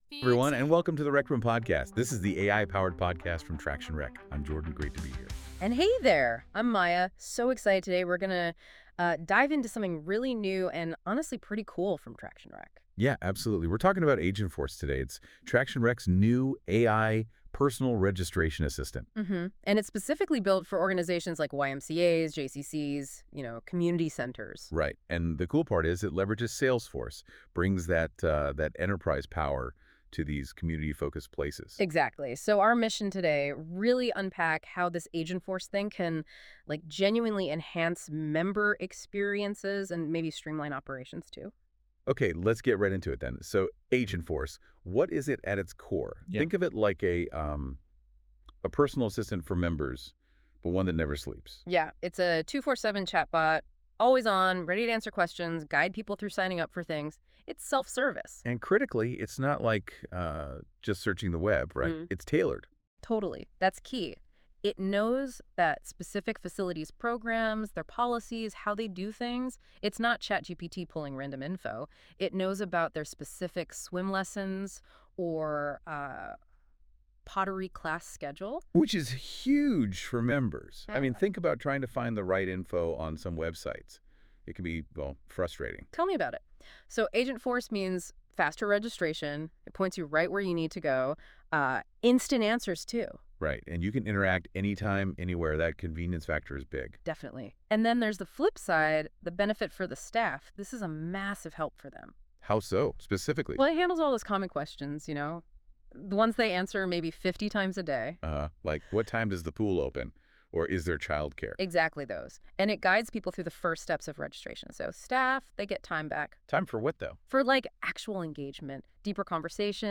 Welcome to The Rec Room — The AI Generated podcast that takes you behind the scenes of the people, passion, and technology driving the world of community and recreation within Traction Rec.